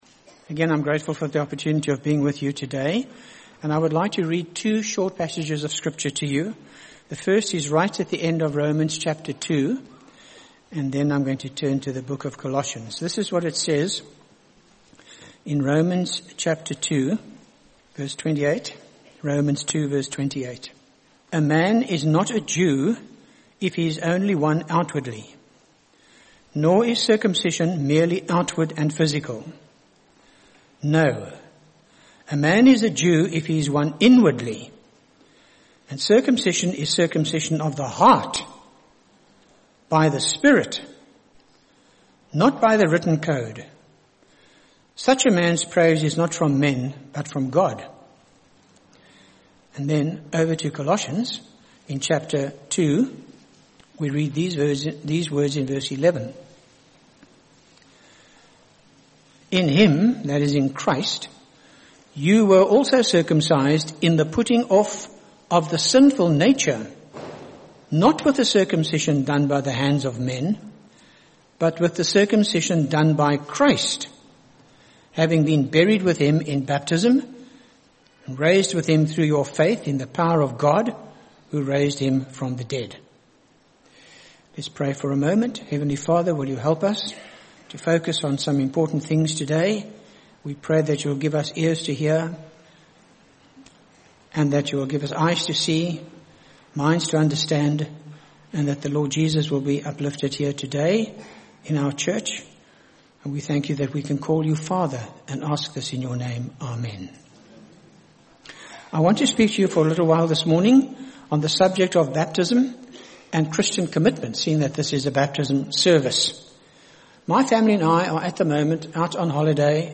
by Frank Retief | Jan 21, 2025 | Frank's Sermons (St James) | 0 comments